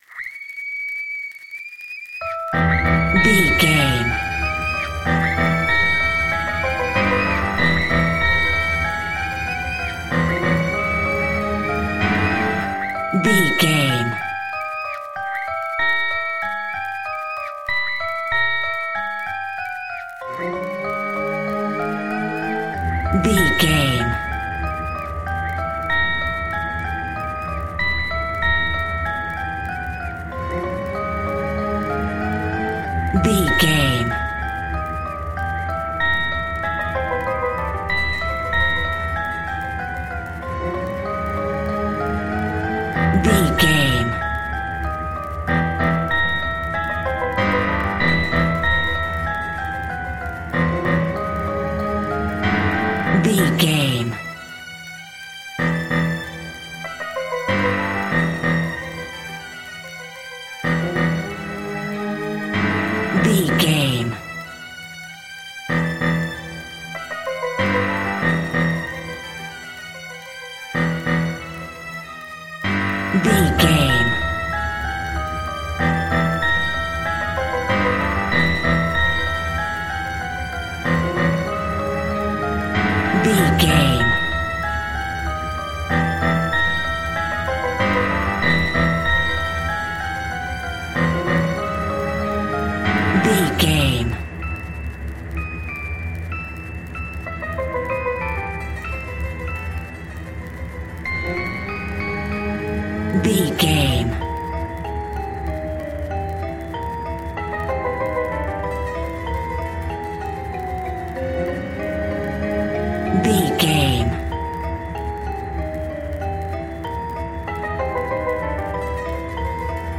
Old Horror Record Music.
In-crescendo
Diminished
ominous
haunting
eerie
strings
piano
synth
pads